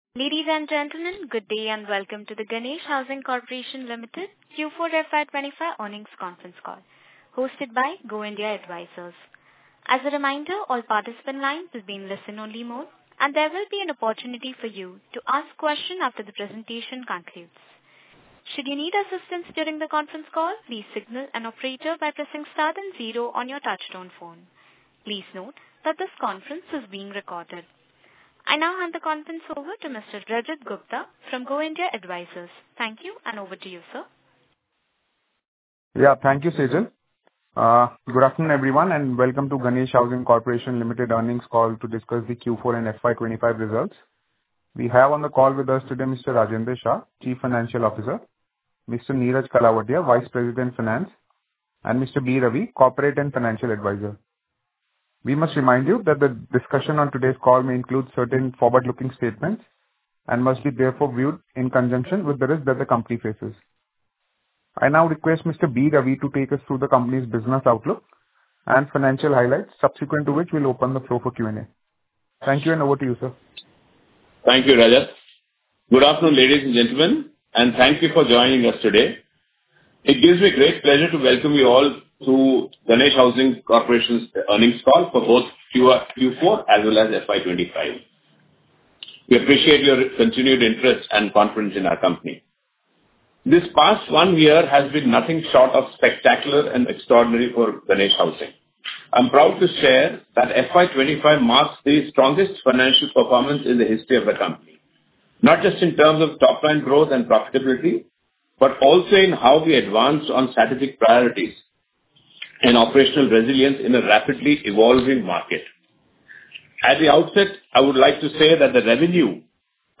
Concalls
q4fy25_earning_call_recording.mp3